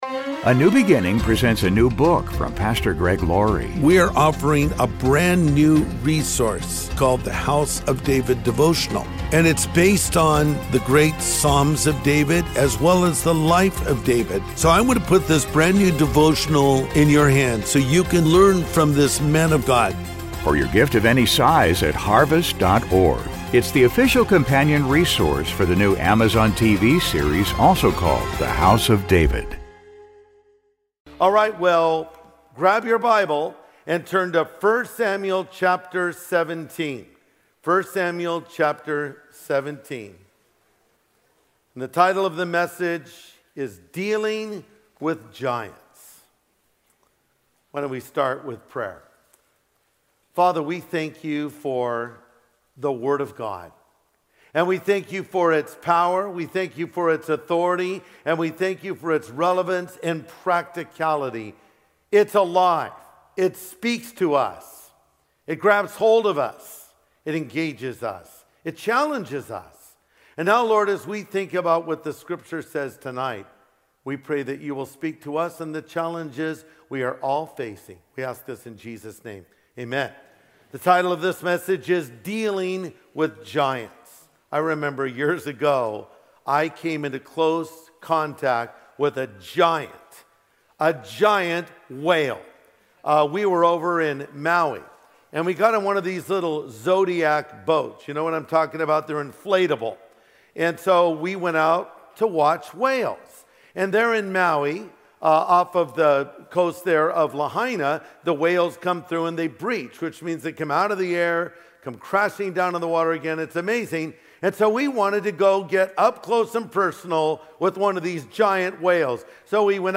What giants do you have in your life? Learn how to deal with them in today's message from Pastor Greg Laurie.